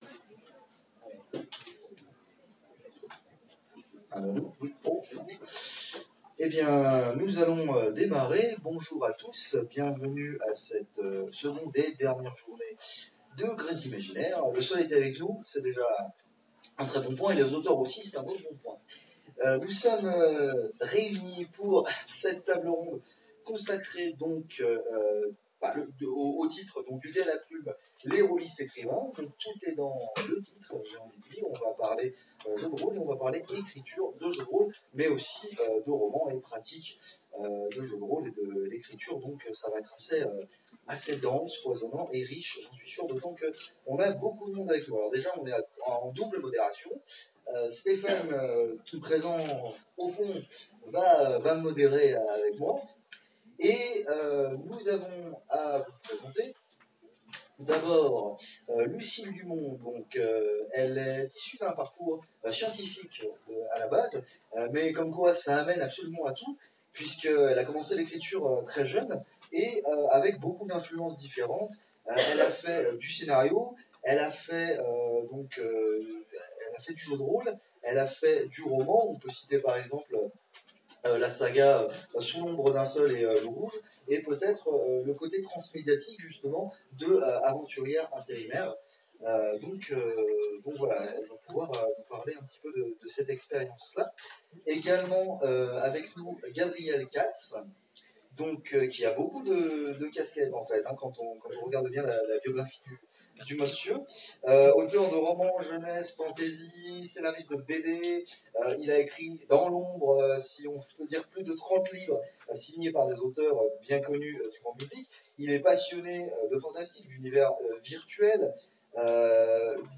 Grésimaginaire 2018 : Table ronde du dé à la plume
Mots-clés Jeu de rôle Conférence Partager cet article
Gresimaginaire_ table_ ronde_Du_De_A_La_Plume.mp3